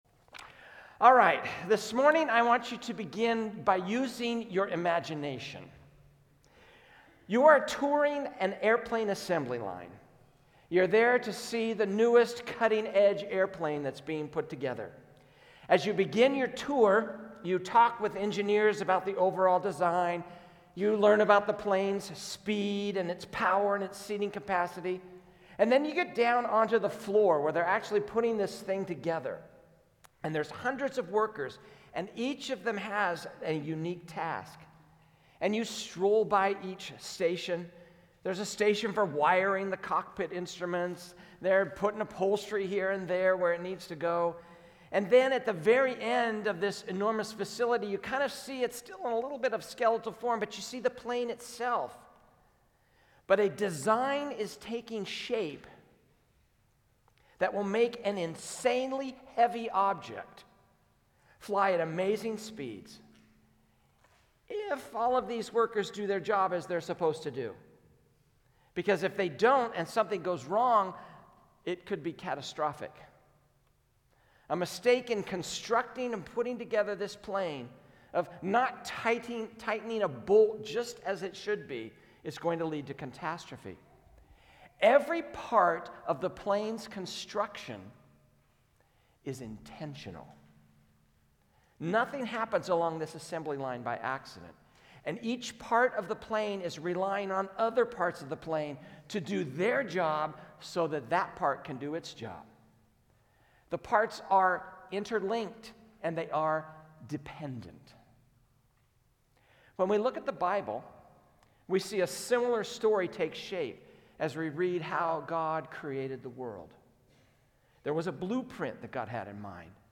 A message from the series "IMAGO DEI."